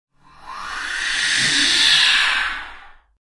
Звуки вампира
Зловещий зов тревожного вампира